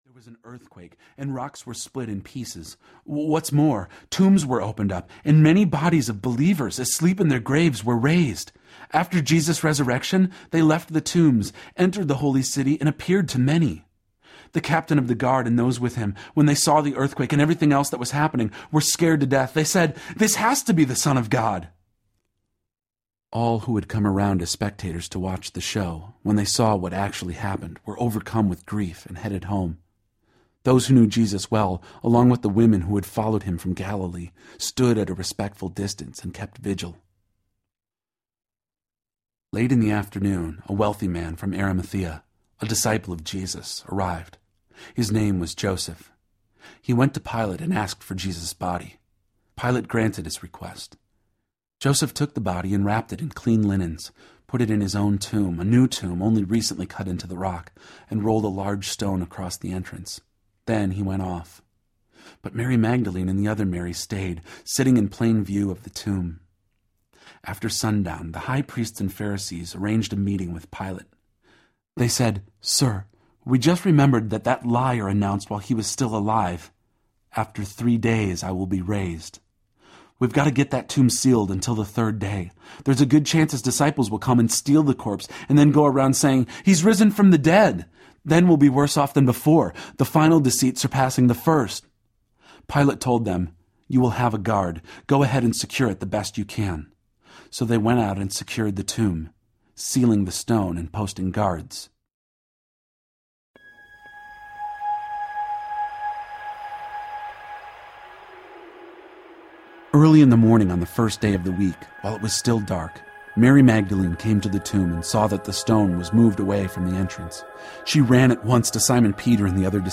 Resurrection Audiobook